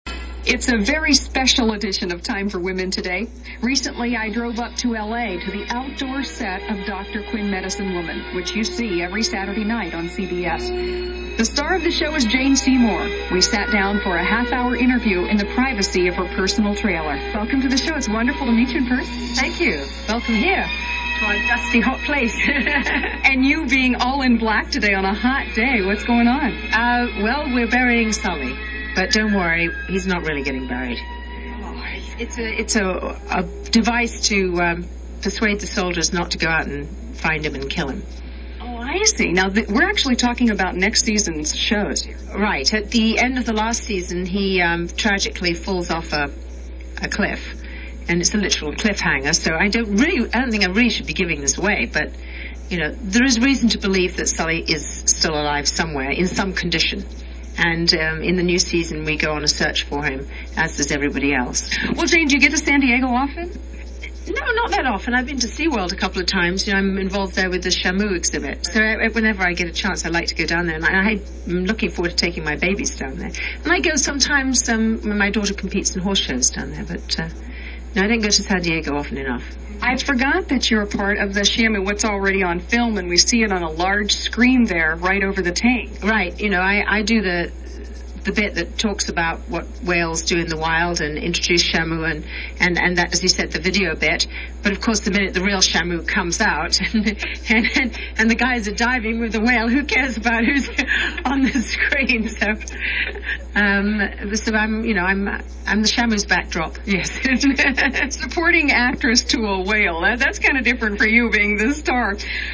Jane Seymour on the set of Dr. Quinn